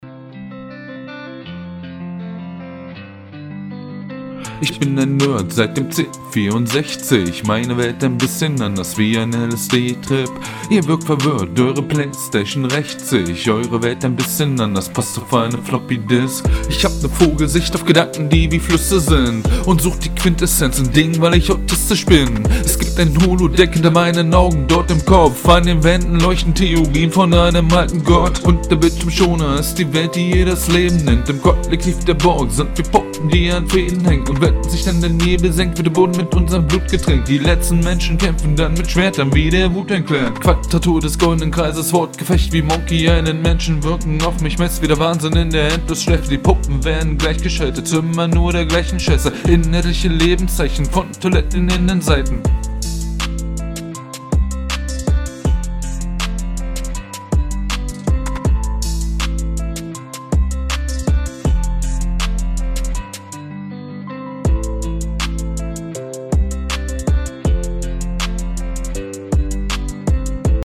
schreibaufnahme in Arbeit